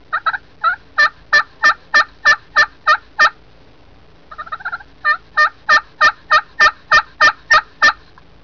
Yelp
It is a series of single-note vocalizations run together and often followed by other sounds.
yelp44.wav